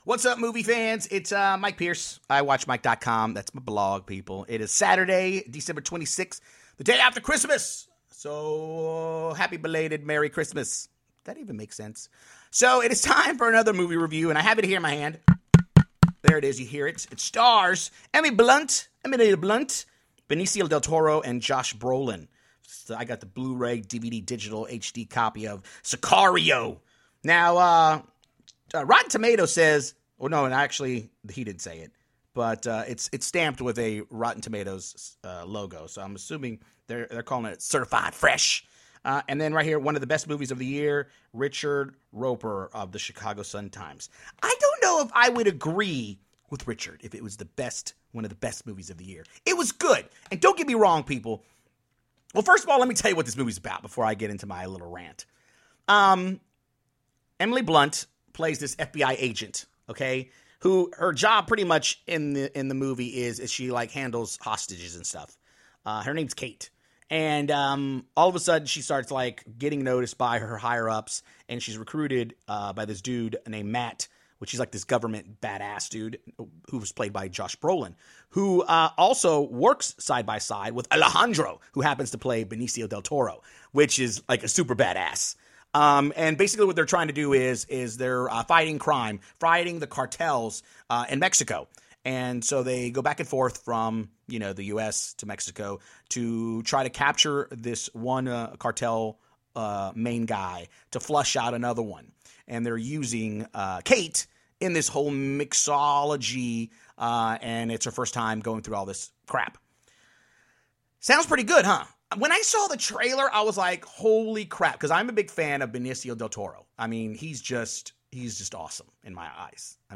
Sicario: Moive Review